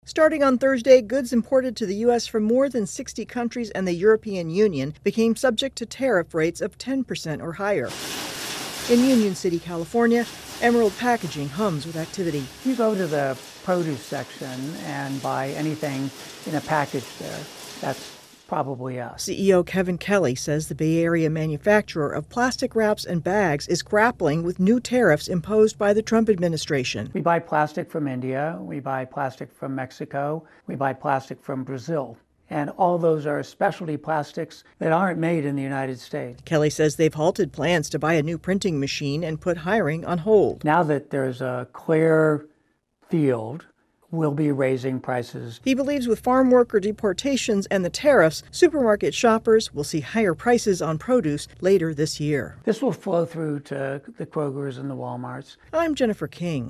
The AP talks with a manufacturer about the impact of the new tariffs on his business and consumers.